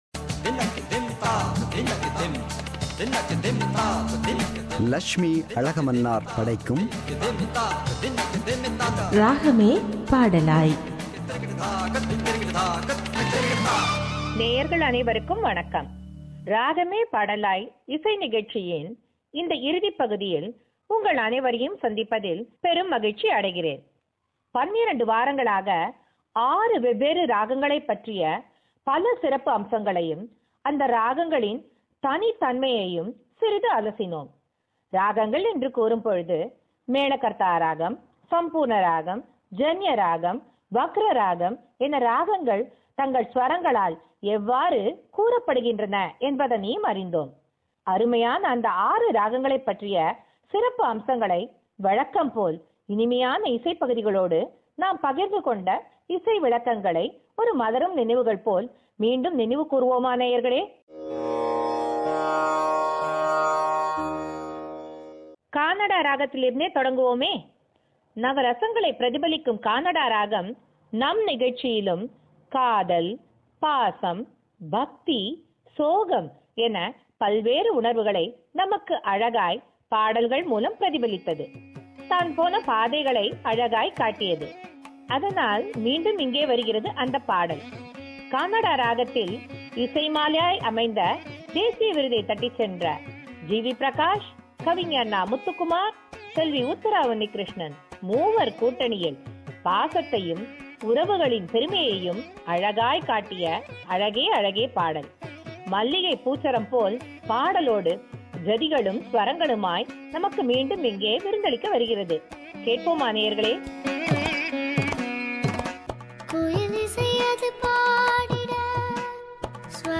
“Ragame Padalaay” – Musical Program – Final Part